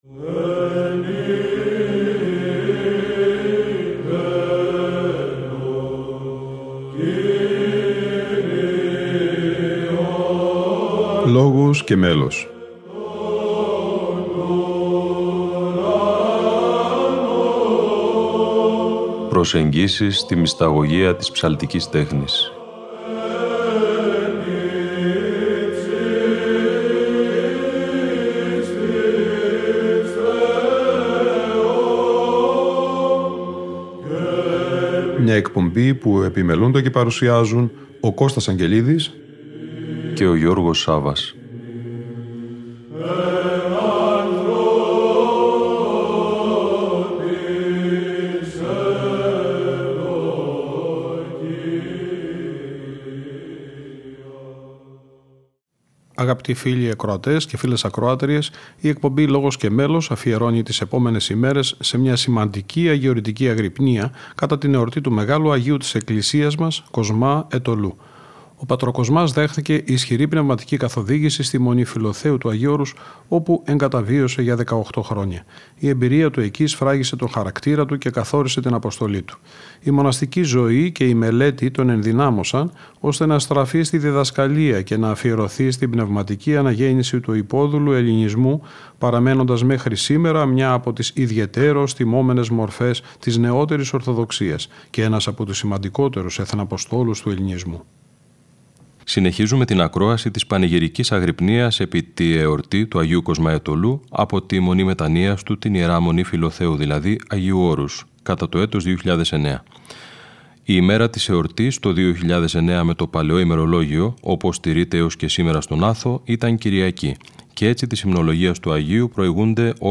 Αγρυπνία Αγ. Κοσμά Αιτωλού - Ι. Μ. Φιλοθέου 2009 (Δ΄)